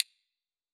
Raise-Your-Wand / Sound / Effects / UI / Minimalist4.wav